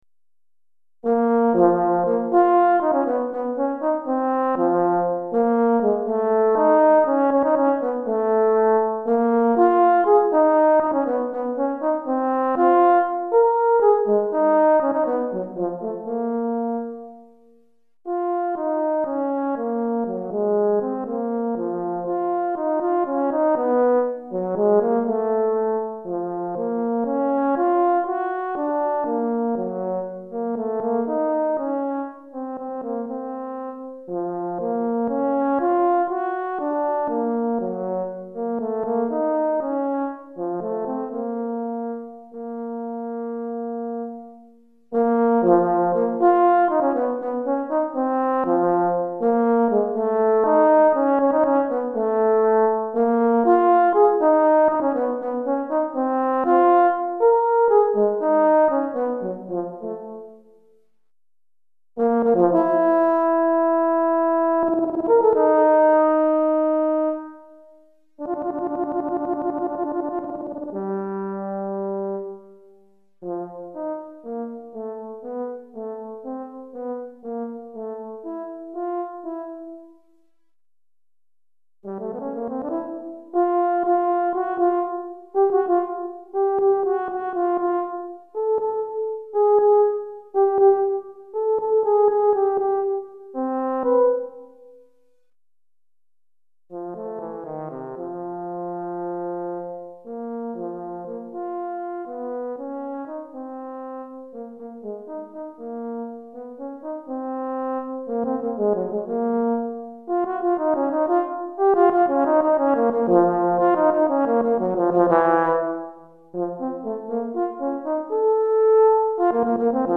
Pour cor solo DEGRE FIN DE CYCLE 1
Cor solo